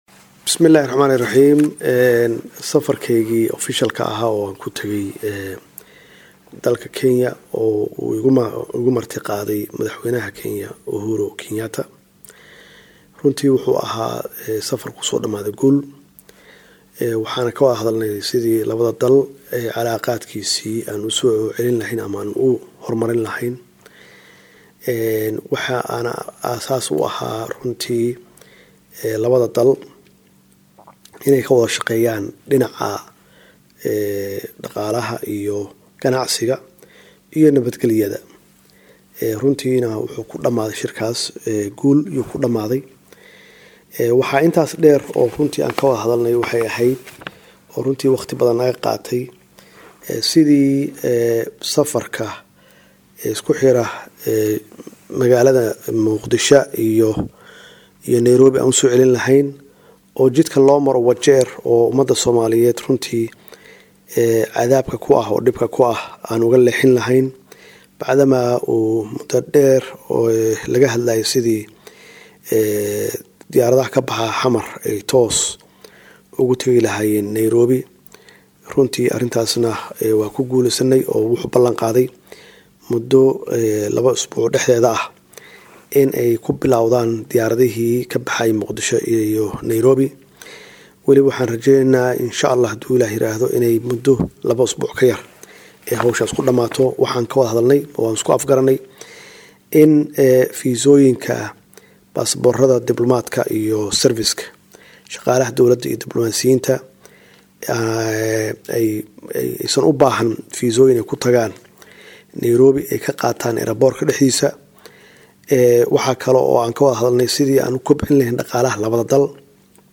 Dhageyso Wareysi uu Madaxweyne Maxamed C/llahi Farmaajo siiyey Warbaahinta Qaranka.